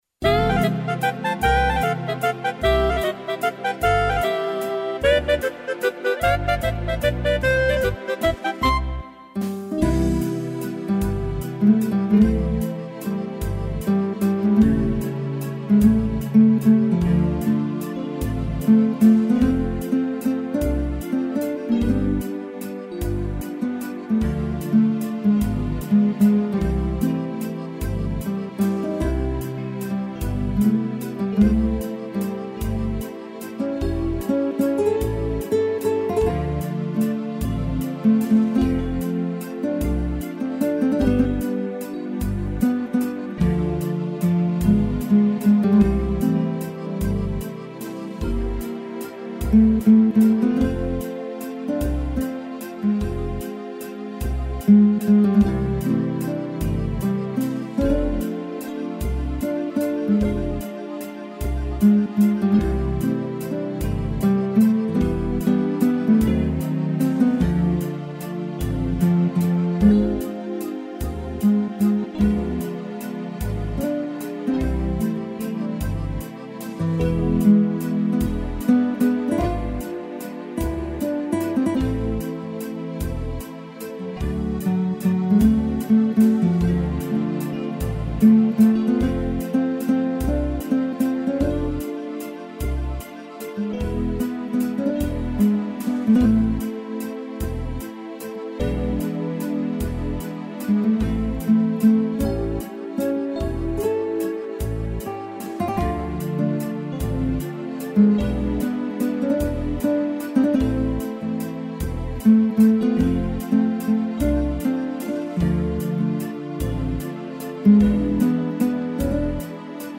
violão
(instrumental)